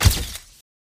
Flash.mp3